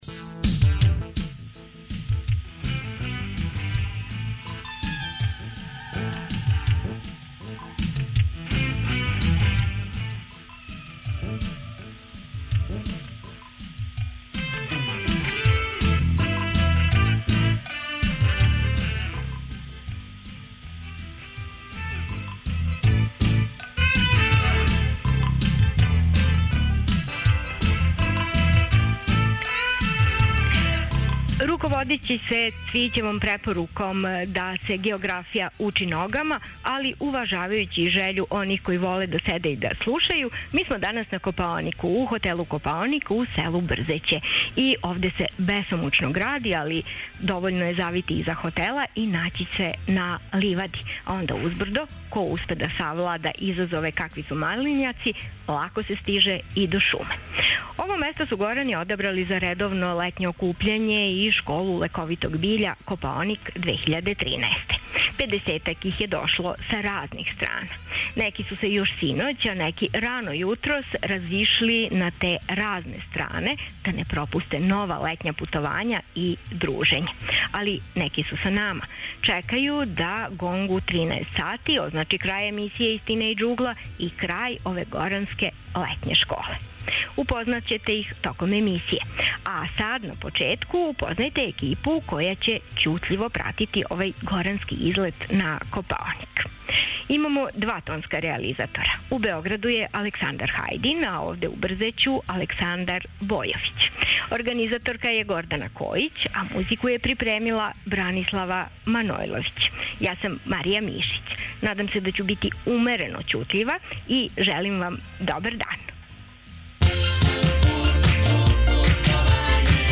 Емисија се реализује са Копаоника из Брзећа, где се управо завршава горанска школа лековитог биља. Шарена екипа састављена од припадника Покрета горана различитих година и еколошког искуства седам дана је у преподневним шетњама и поподневним предавањима упознавала биљни свет Копаоника.